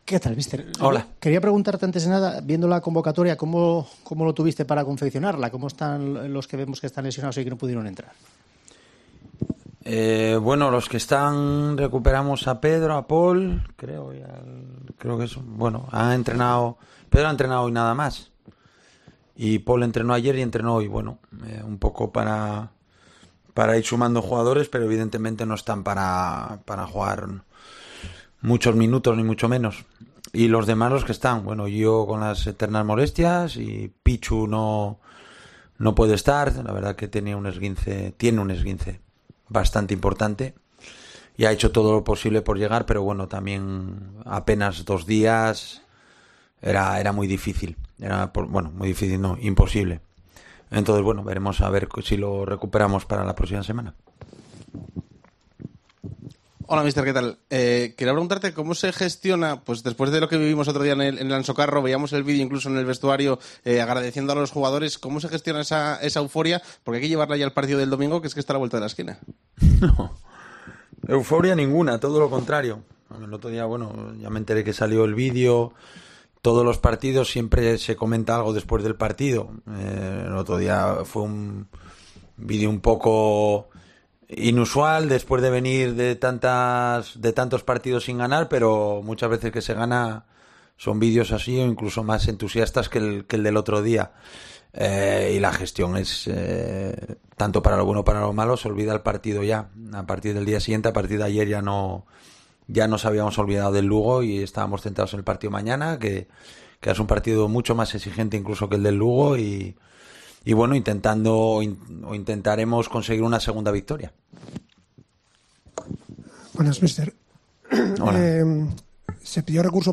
Rueda de prensa Abelardo (previa Cartagena)
Abelardo Fernández, entrenador del Sporting, compareció ante los medios de comunicación en la previa de la jornada 20 de LaLiga SmartBank frente al Cartagena.